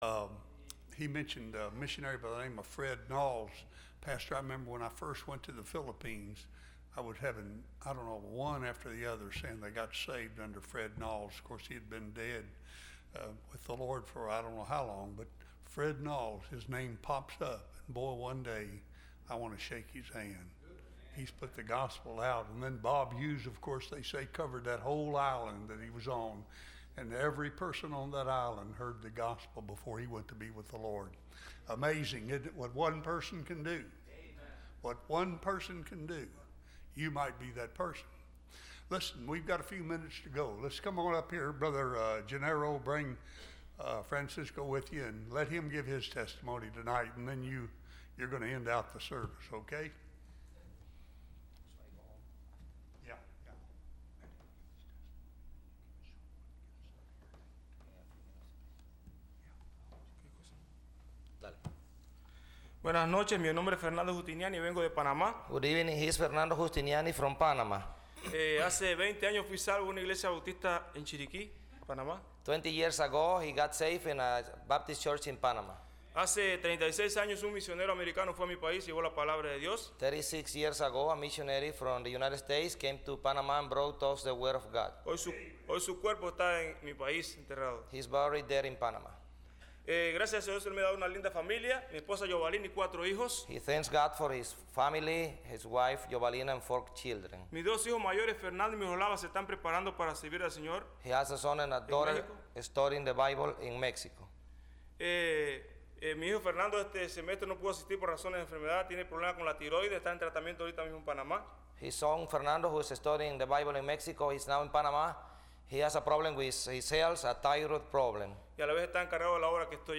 Listen to Message
Service Type: Missions Conference